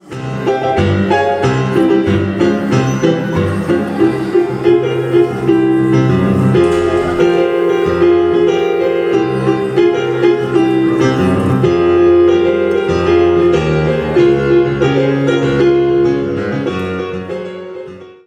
piano
Concert à l'école.